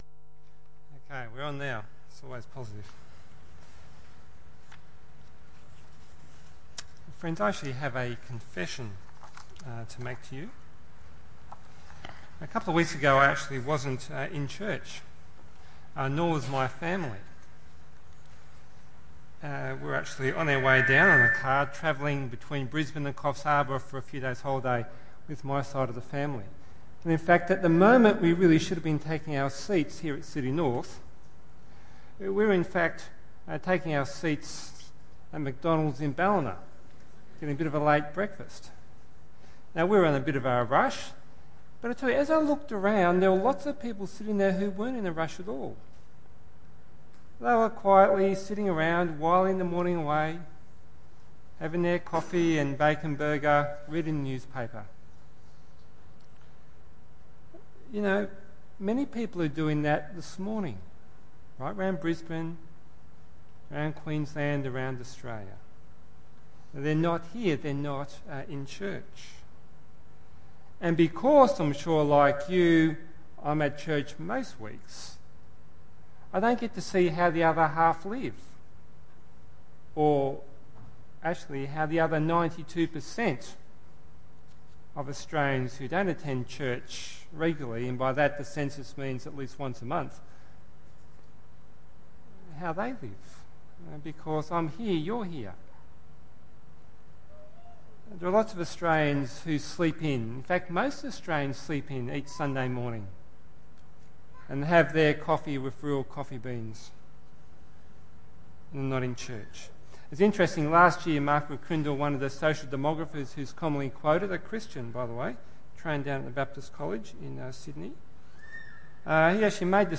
Guest Speaker
Hebrews 10:19-25 Tagged with Sunday Morning